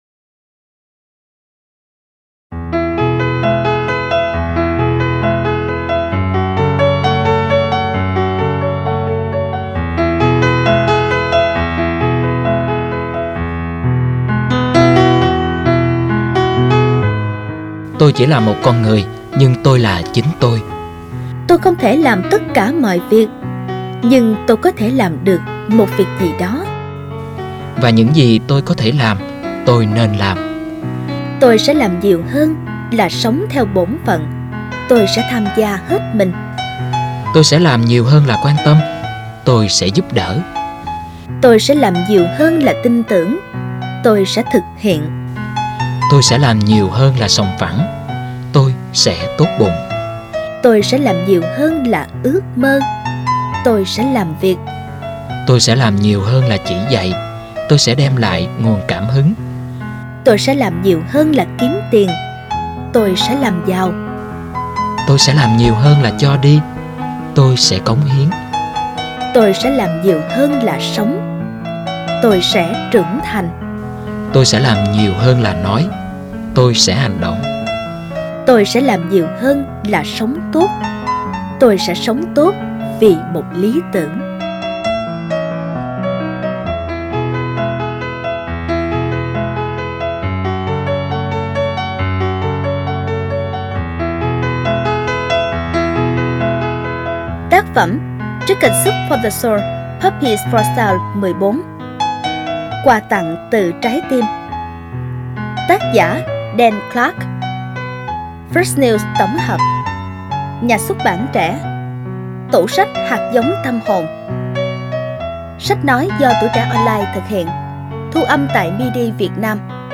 Sách nói | Tôi sẽ làm nhiều hơn